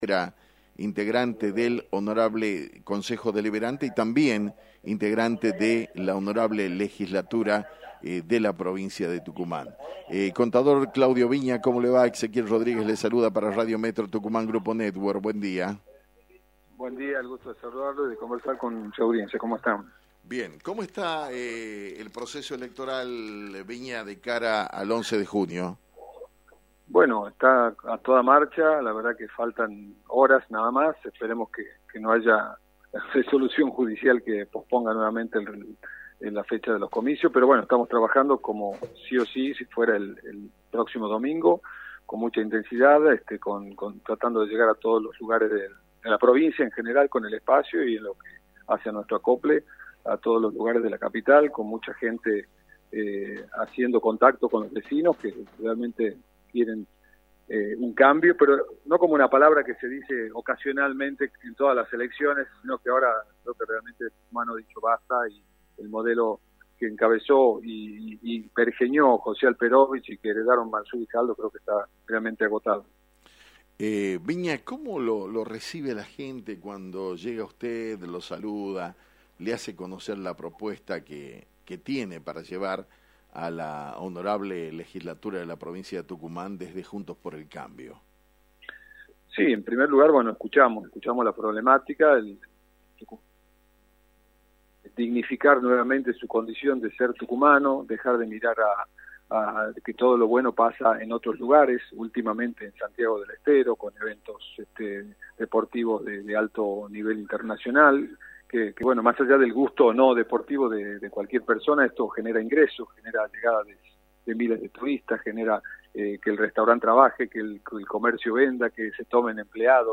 El Contador Público Nacional Claudio Viña, Secretario de Ingresos Municipales de San Miguel de Tucumán y candidato a Legislador por Juntos por el Cambio, analizó en Radio Metro Tucumán, por la 89.1 el escenario político y electoral de la provincia, en la previa de las elecciones establecidas para el 11 de junio.